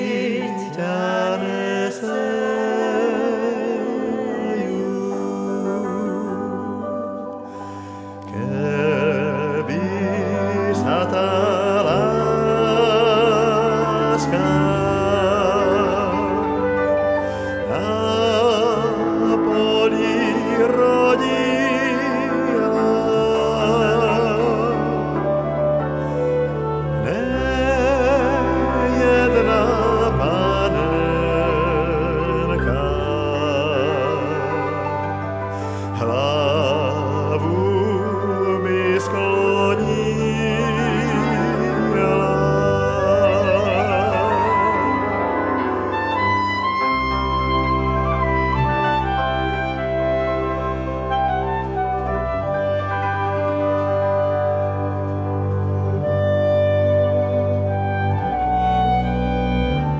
Audiobook
Read: Radoslav Brzobohatý